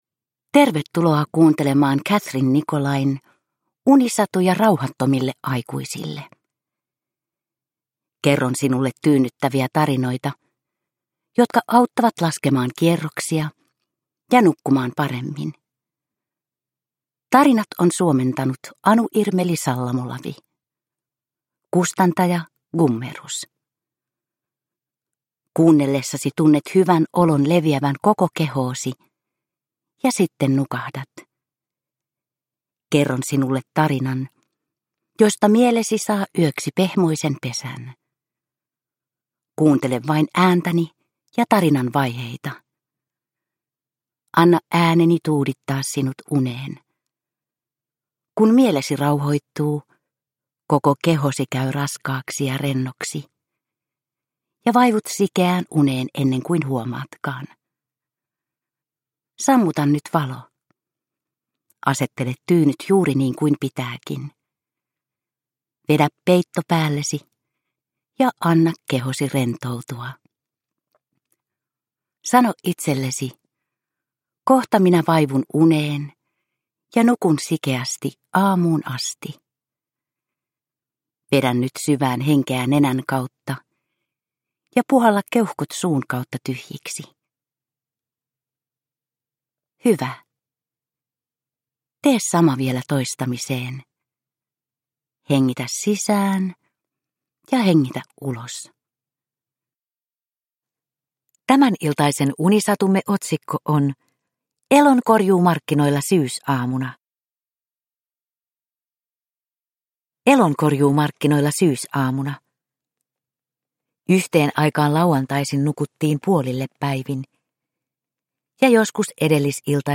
Unisatuja rauhattomille aikuisille 50 - Elonkorjuumarkkinoilla syysaamuna – Ljudbok – Laddas ner